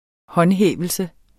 Udtale [ ˈhʌnˌhεˀvəlsə ]